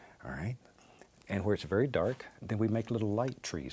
voices / eng / adult / male
BobRoss_22khz.wav